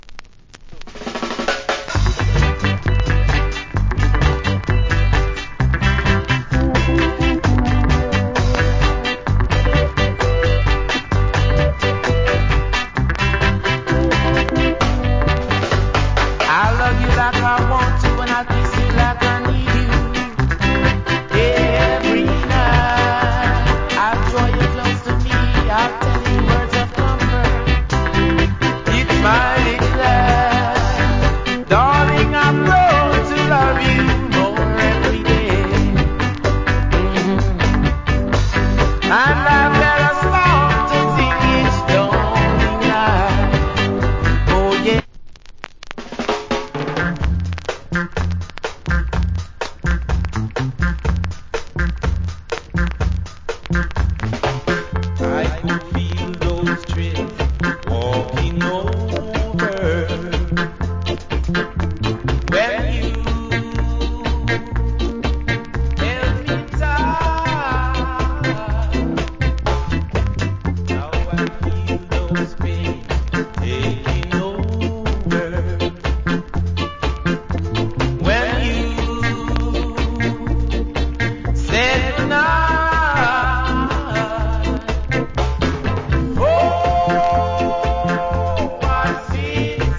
Old Hits Early Reggae.